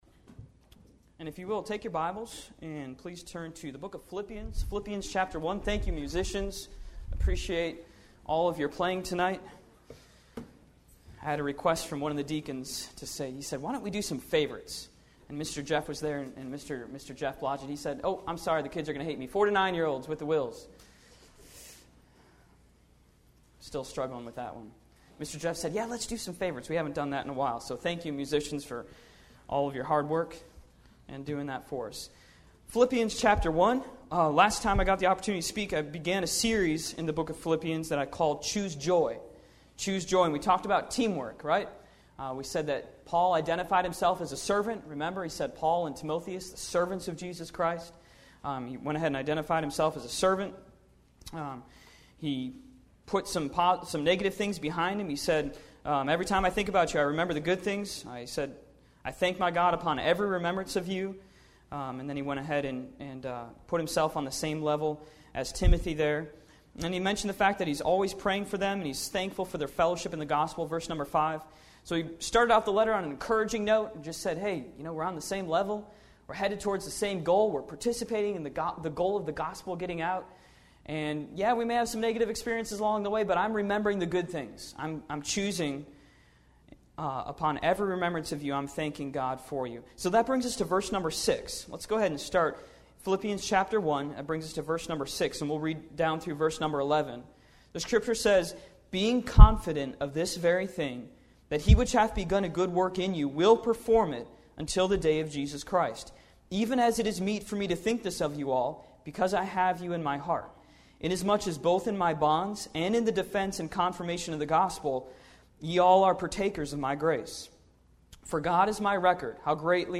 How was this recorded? May 25, 2014 – PM – Tapped Potential – Bible Baptist Church